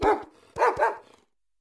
Cri de Briochien dans Pokémon Écarlate et Violet.